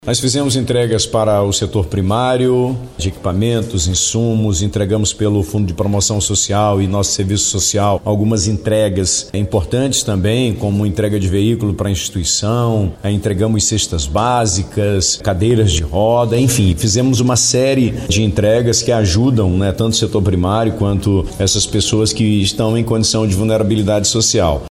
SONORA-2-WILSON-LIMA.mp3